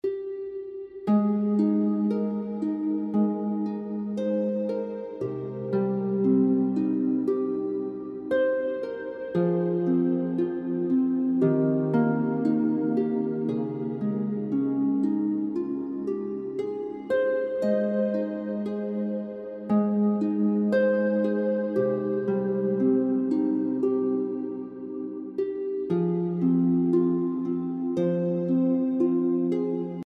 harp Related products Sale!